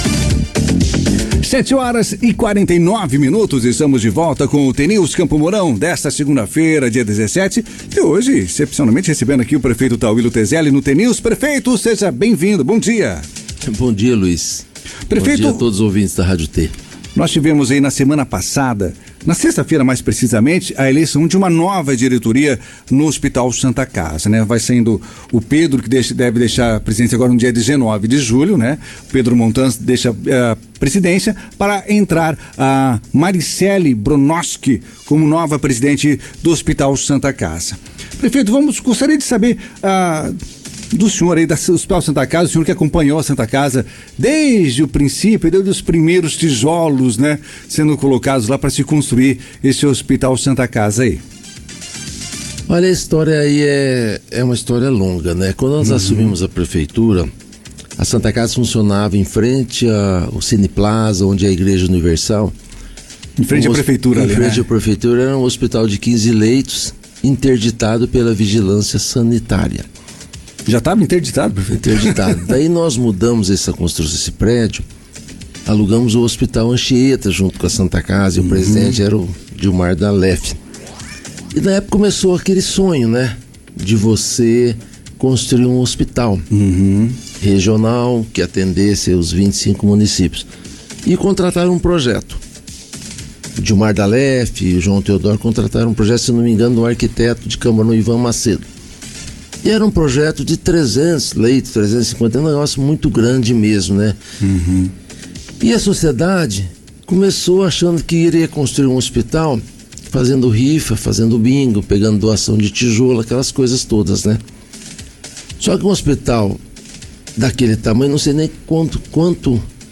O jornal T News, da Rádio T FM, entrevistou o prefeito de Campo Mourão, Tauillo Tezelli, nesta segunda-feira, dia 17.